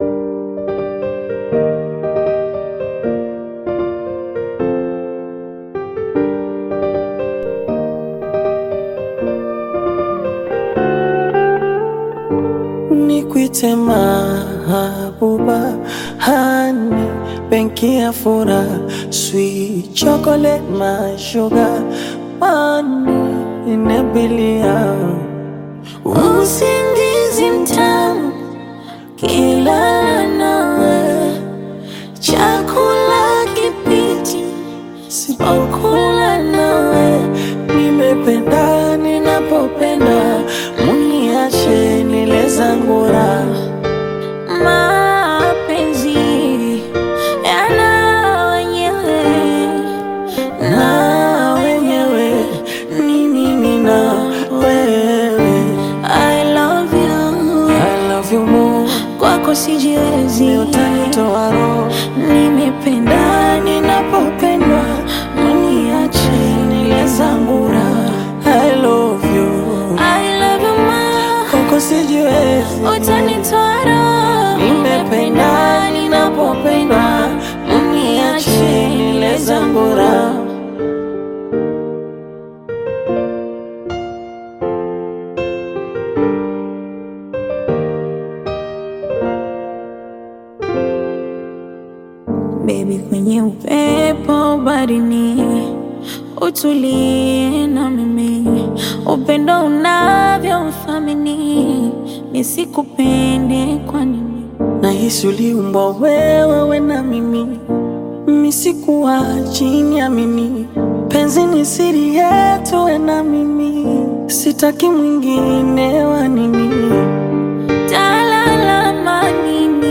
Bongo Flava song
This catchy new song